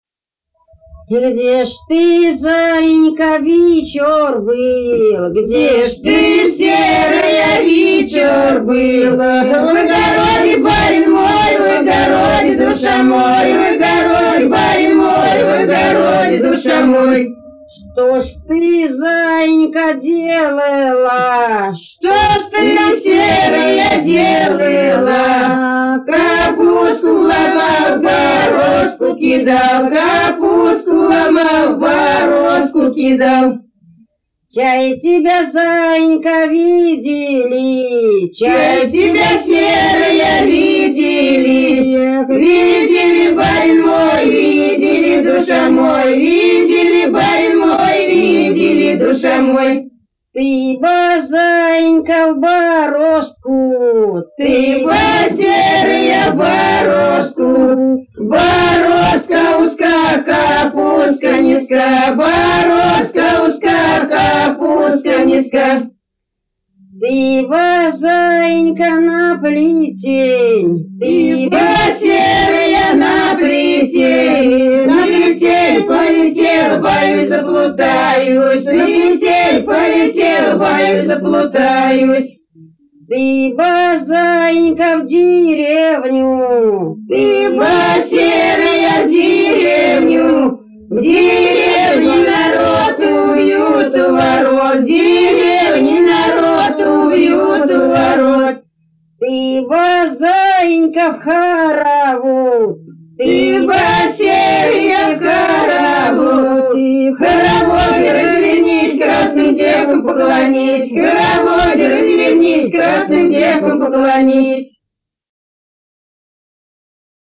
игровая